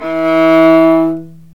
Index of /90_sSampleCDs/Roland L-CD702/VOL-1/STR_Viola Solo/STR_Vla3 Arco nv
STR VIOLA 02.wav